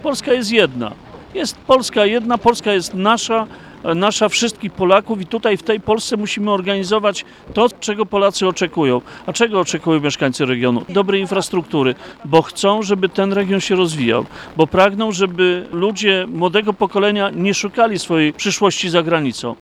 – Wsłuchujemy się w potrzeby mieszkańców – mówi szef resortu infrastruktury.